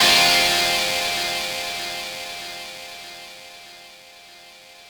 ChordFm.wav